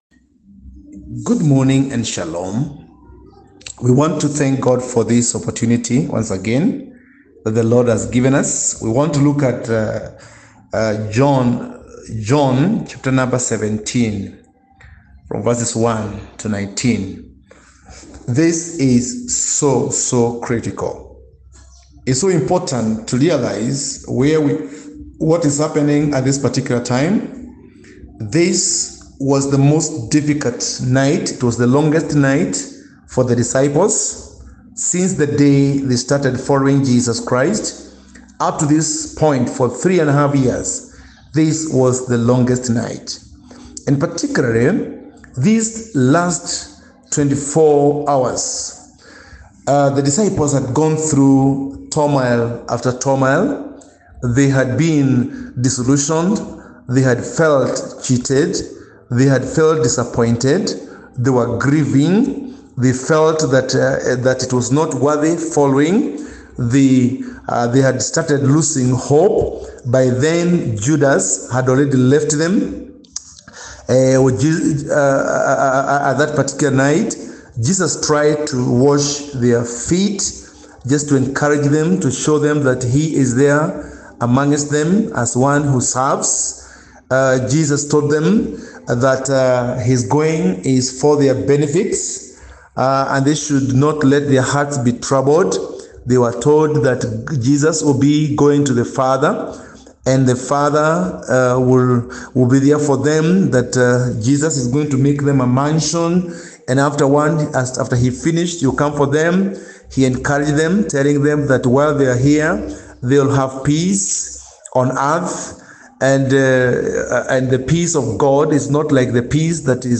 Winning the souls through intercessory prayer John 17:1-19 John-17-1-19 Audio Summary John 17:1-19 Scripture Focus : John 17:1-19—Jesus’ prayer during the longest, most difficult night for the disciples.